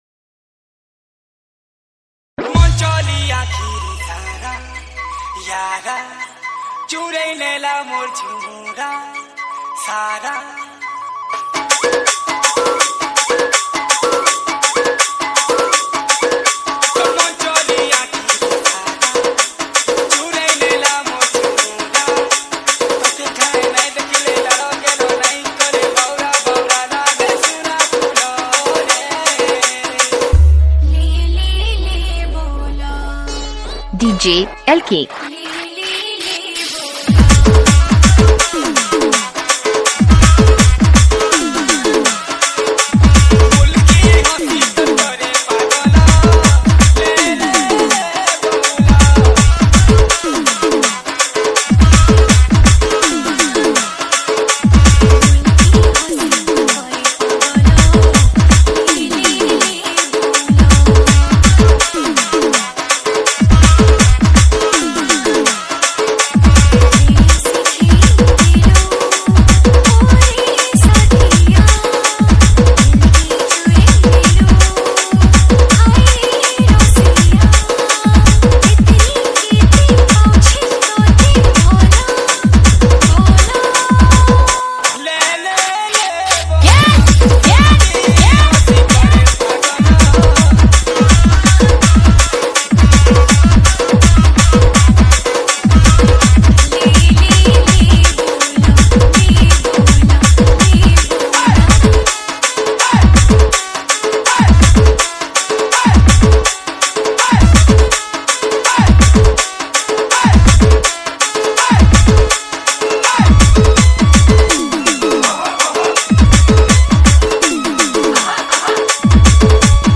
SAMBALPURI LOVE DJ REMIX